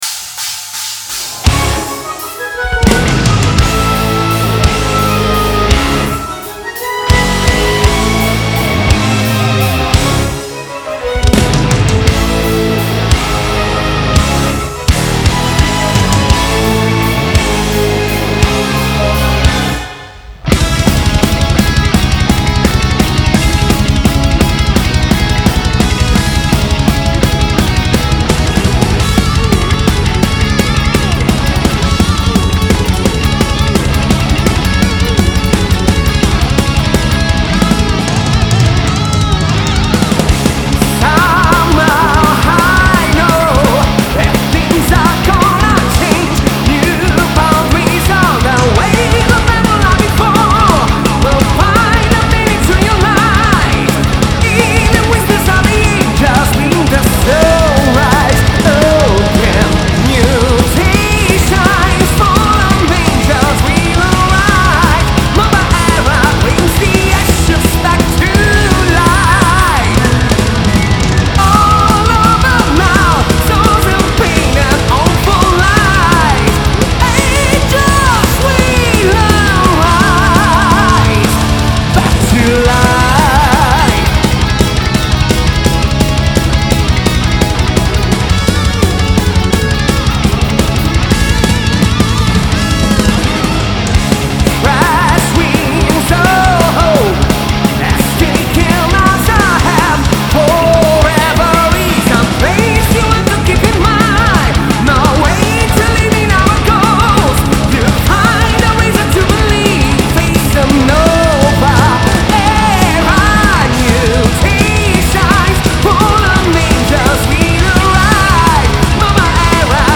Genre: Metal
Recorded at Fascination Street Studios.